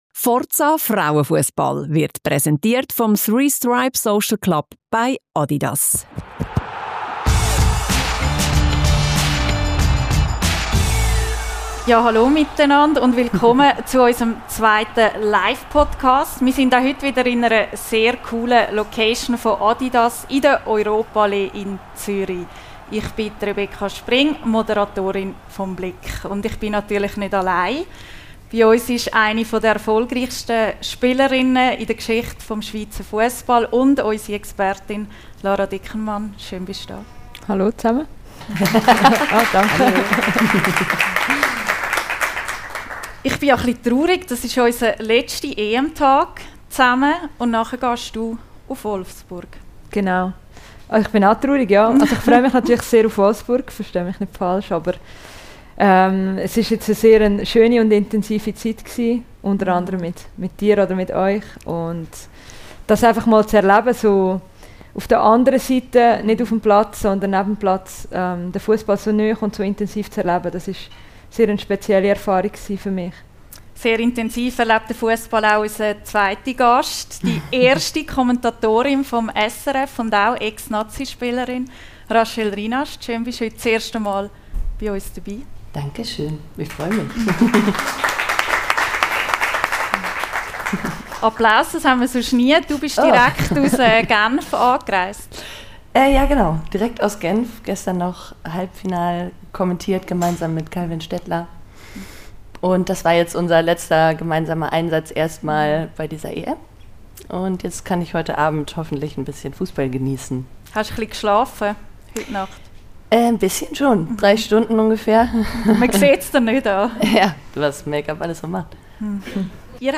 #12 - Rachel Rinast und Lara Dickenmann im grossen EM-Live-Talk ~ FORZA! Frauen. Fussball. Podcast